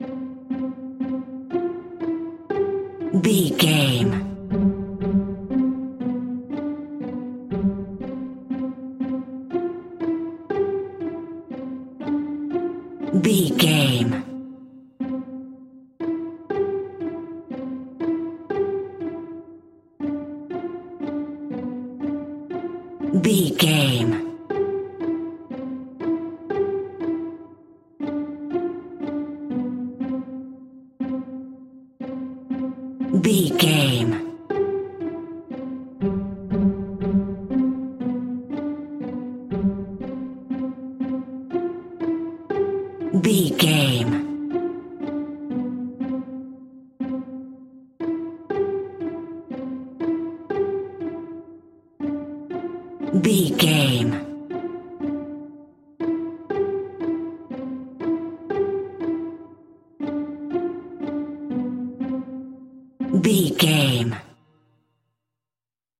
Ionian/Major
nursery rhymes
kids music